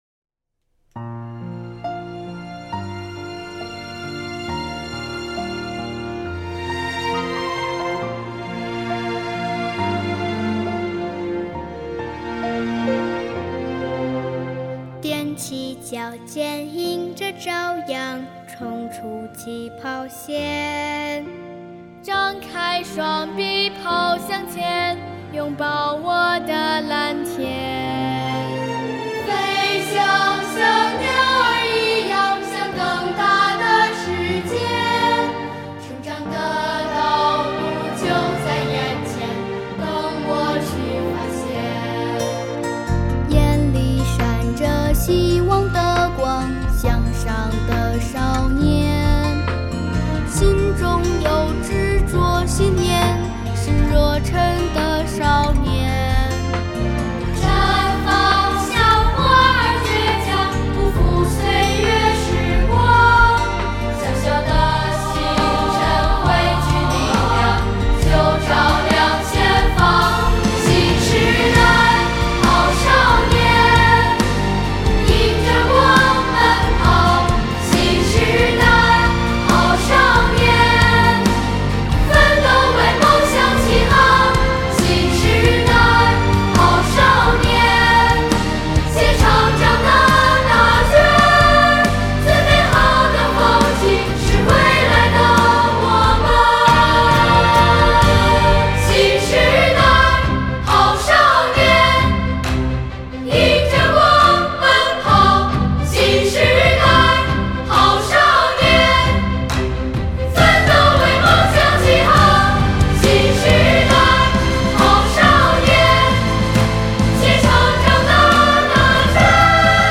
10月24日下午，首都文明办、市教委、团市委、市妇联、市关工委联合举办“童心颂祖国 逐梦向未来——2024年首都‘新时代好少年’先进事迹发布仪式”，向社会发布2023、2024年度60位首都“新时代好少年”事迹。